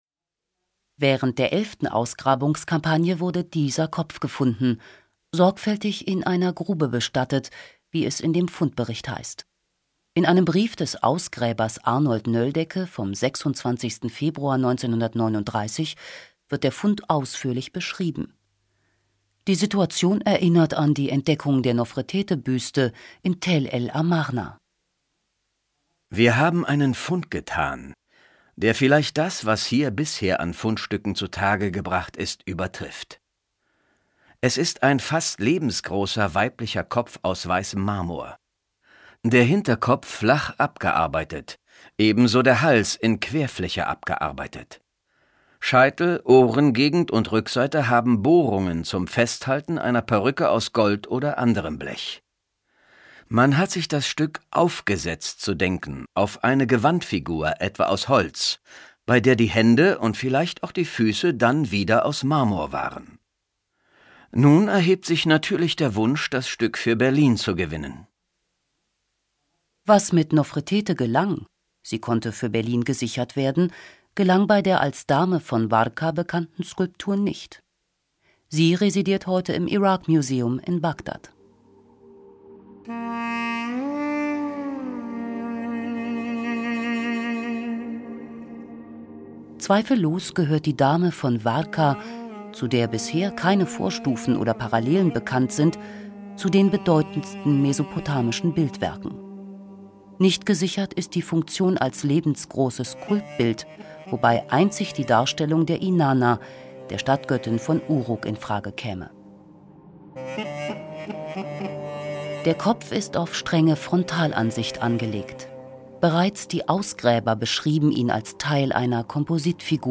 These audioguides are packed with insightful background information on the Epic of Gilgamesh and the ancient megacity Uruk.